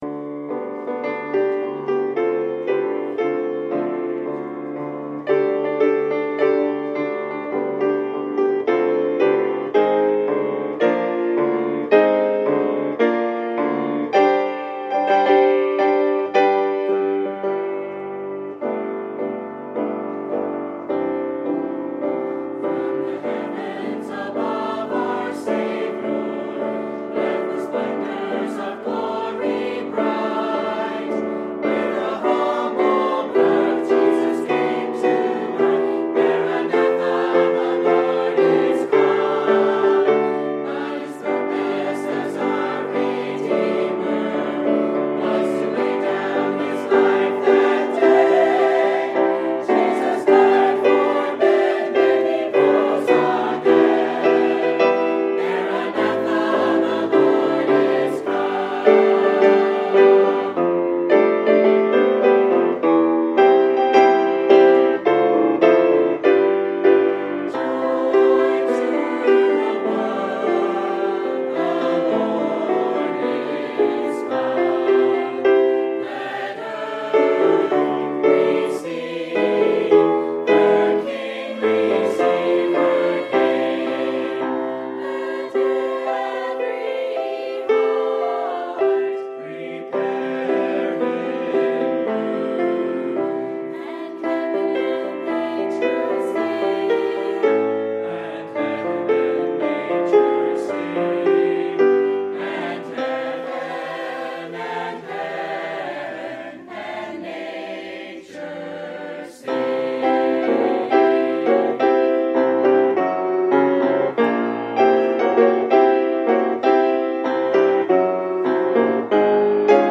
Sunday, December 18, 2016 – Adult Christmas Music Program